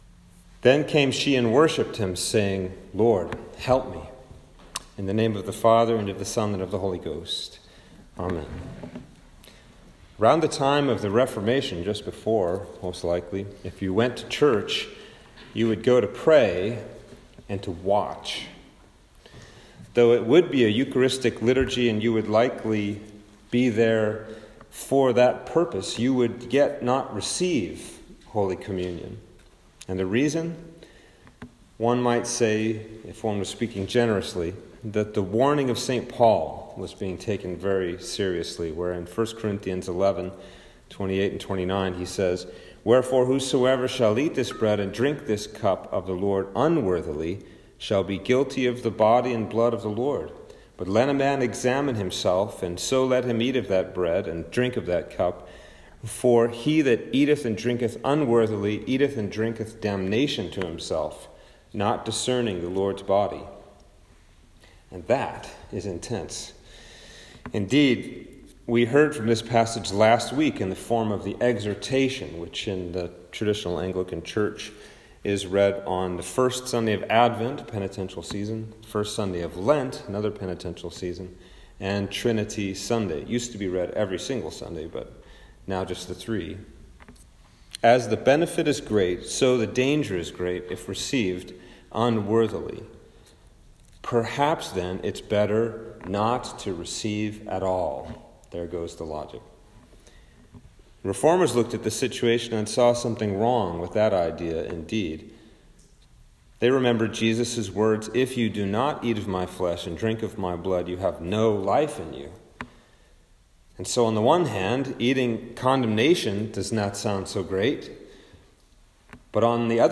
Sermon for Lent 2
Sermon-for-Lent-2-2021.m4a